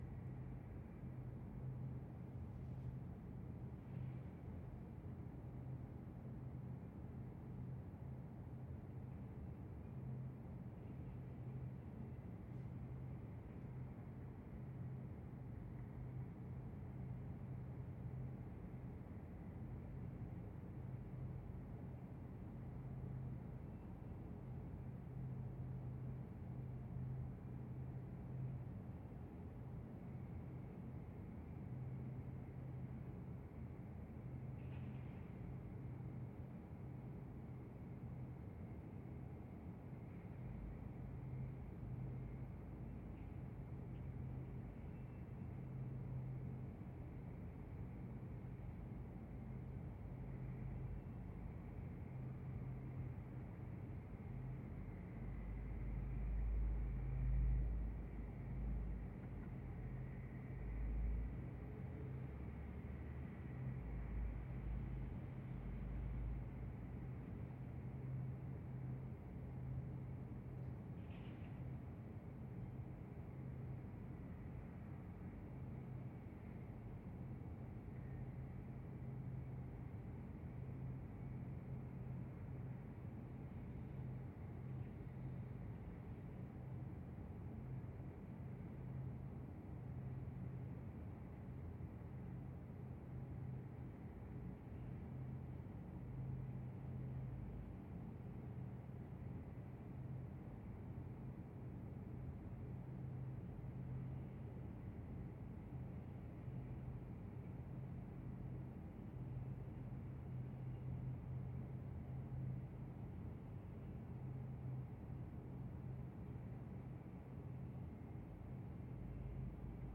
На этой странице собраны звуки пустоты – завораживающие, мистические и медитативные аудиозаписи.
Тишина покинутого офиса